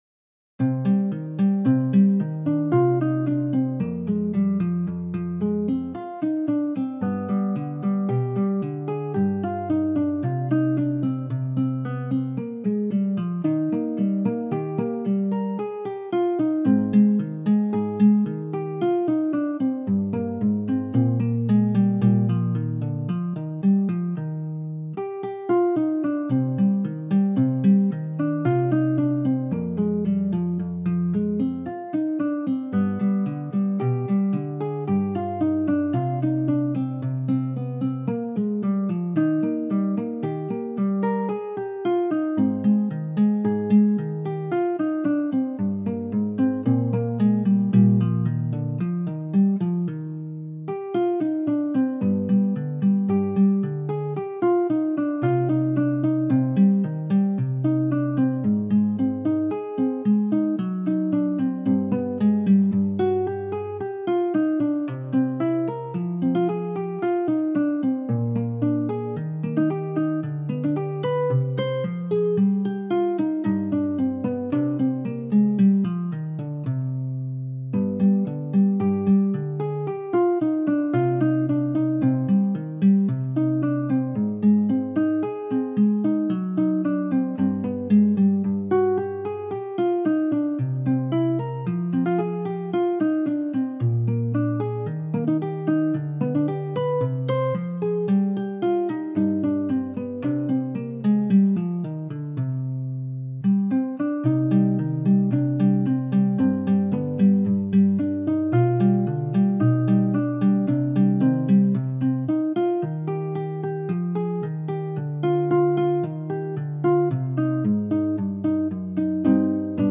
Arranged for Guitar